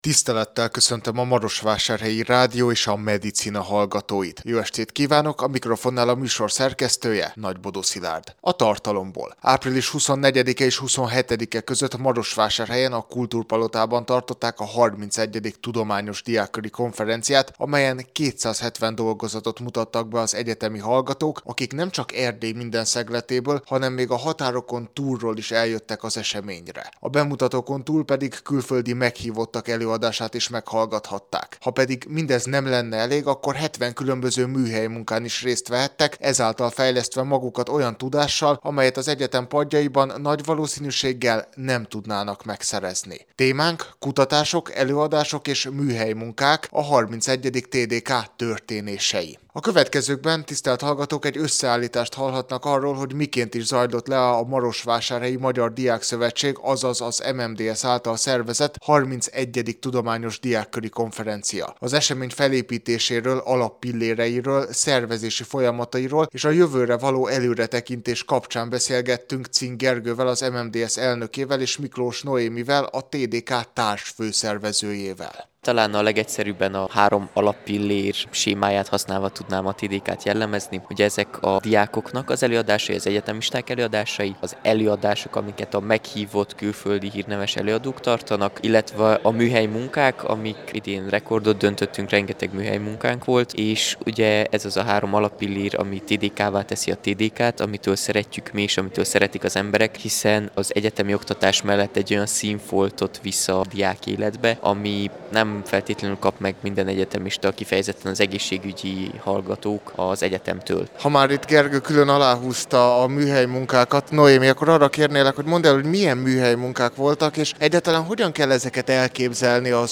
A soron következő Medicinában a 31. Tudományos Diákköri Konferencián készült összeállításunkat hallgathatják meg!